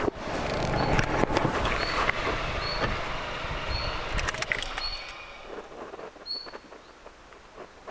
Sitten kuului ilmeistä idäntiltaltin ääntelyä.
ilmeinen idäntiltaltti?